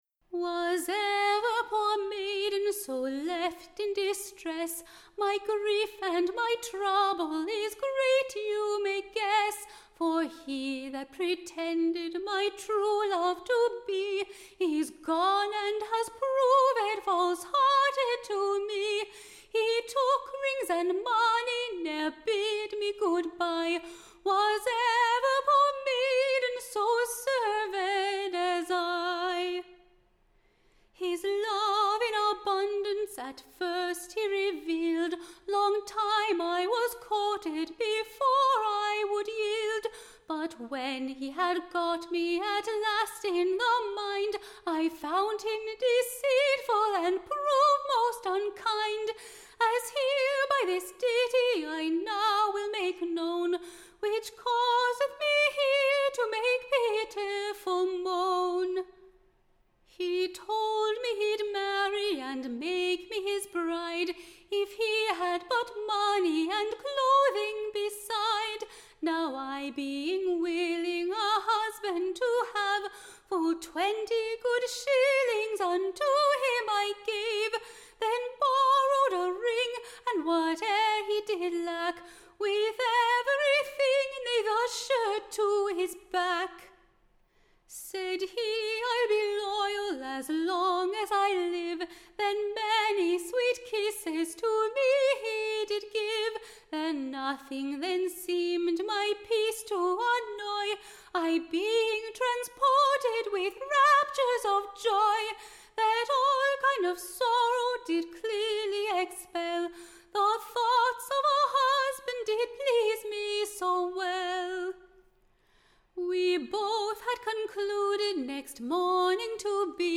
Ballad
Tune Imprint To the Tune of, My Life and my Death.